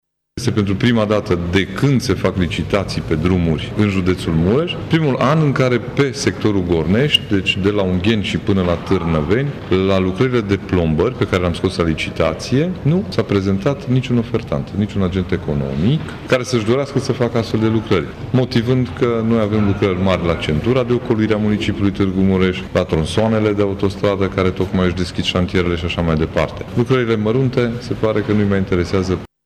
Dobre, la ședința de consiliu județean, de astăzi, a afirmat că au apărut firme care nu se prezintă la licitații pentru plombări de drumuri județene, plătite cu sume mici.
Constructorii preferă marile proiecte, de tip autostrăzi sau șosele de centură, a explicat Ciprian Dobre: